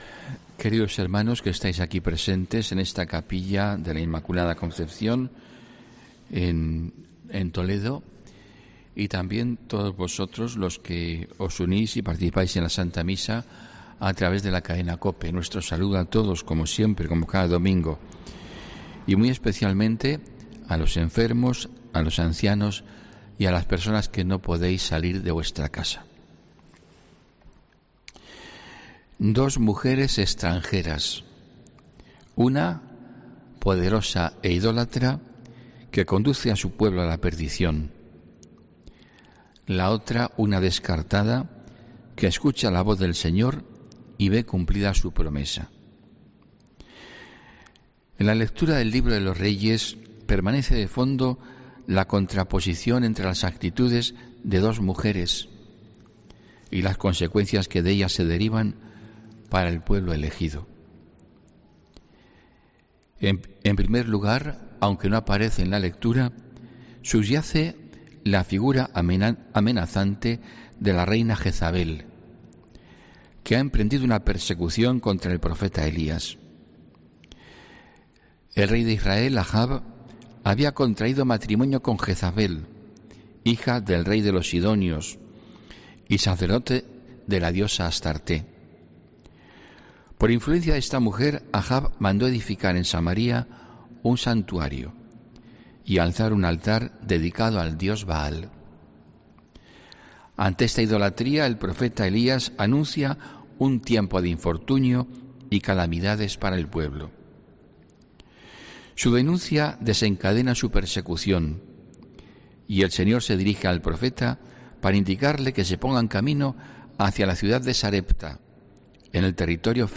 HOMILÍA 7 NOVIEMBRE 2021